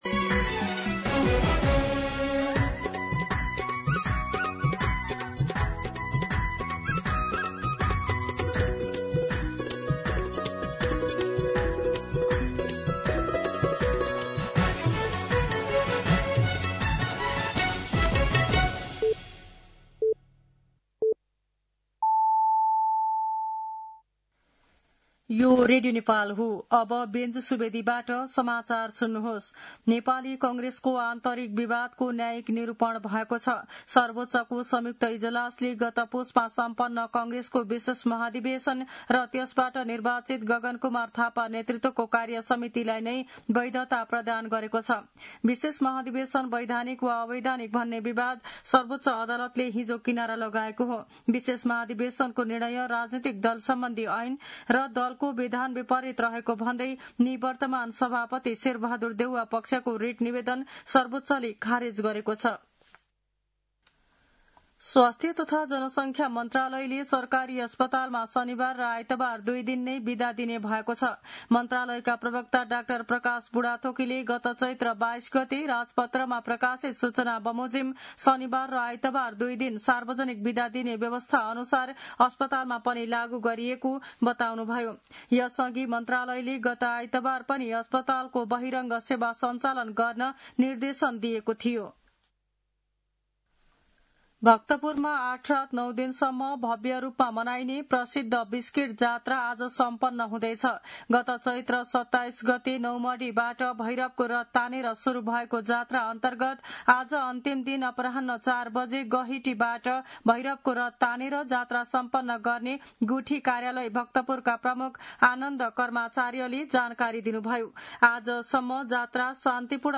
दिउँसो १ बजेको नेपाली समाचार : ५ वैशाख , २०८३
1-pm-Nepali-News-1.mp3